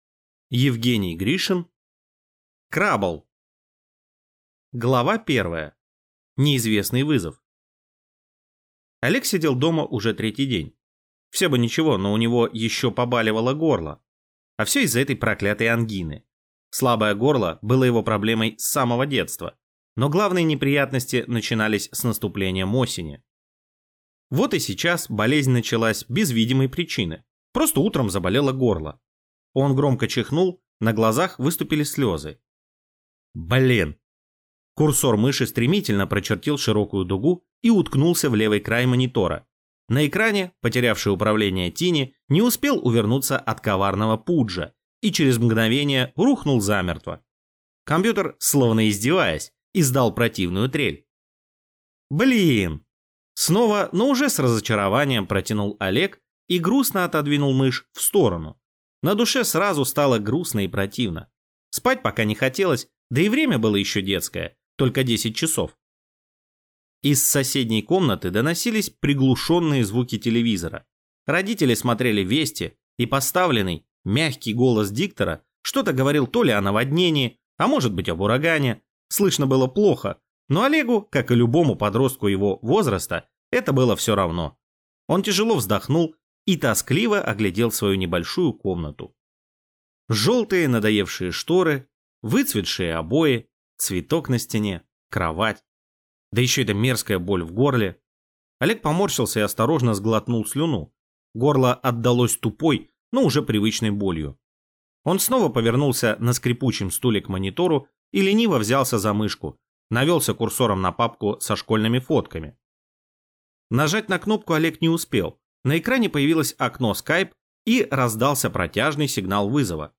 Аудиокнига Крабл | Библиотека аудиокниг